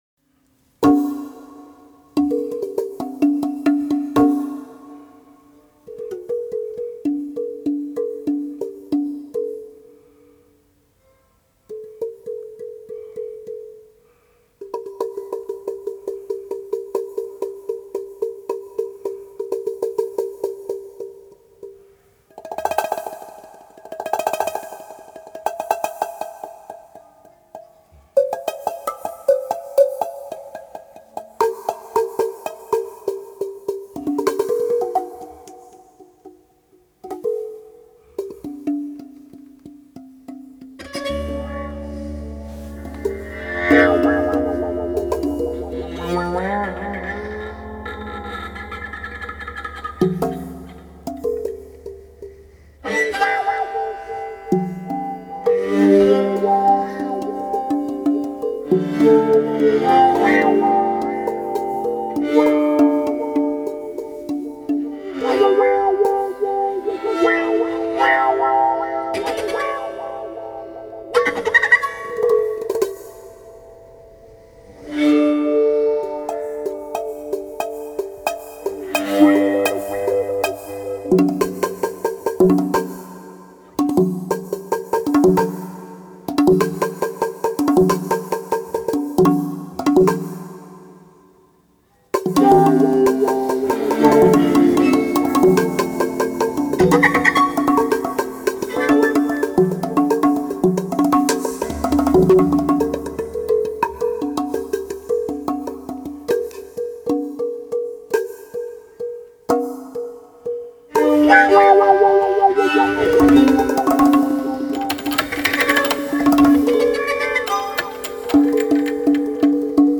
Gendung (Indonesian Drums)
Xylopt and drum kit